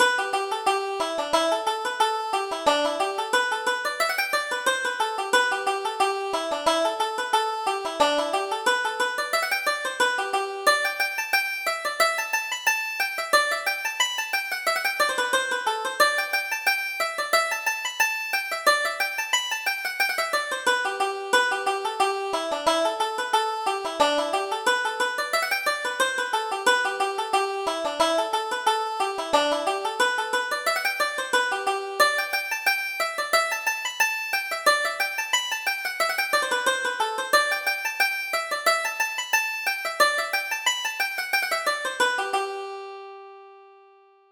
Reel: The Mourne Mountains